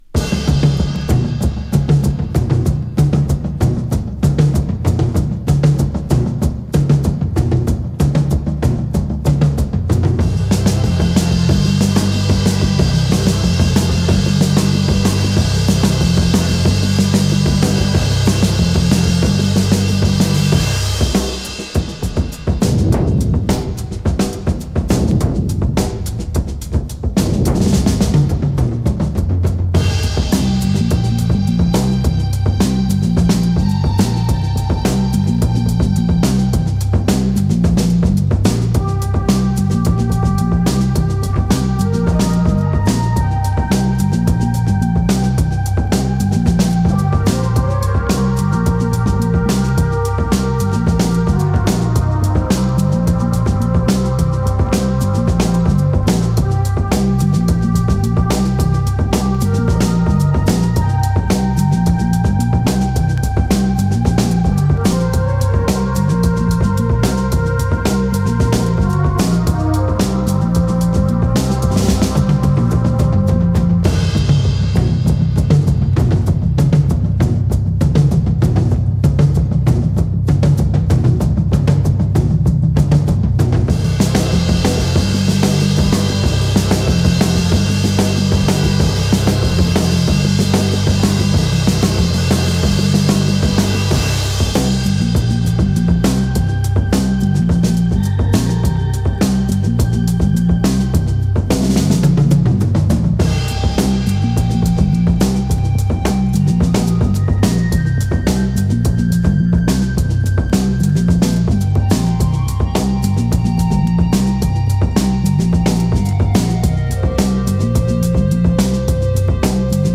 US80sのオブスキュア・シンセサイザーウェイヴ。パワフルな80sエレクトロニック。